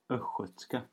Ääntäminen
Ääntäminen Tuntematon aksentti: IPA: /ˈœstjøːtska/ IPA: /ˈœɧœtska/ Haettu sana löytyi näillä lähdekielillä: ruotsi Kieli Käännökset englanti dialect of the Swedish province Östergötland Artikkeli: en .